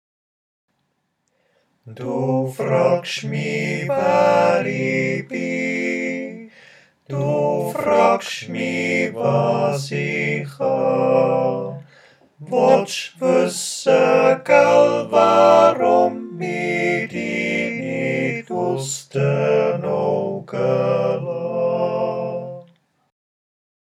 Alles zusammen gesungen
5_dufragschmi_tutti.mp3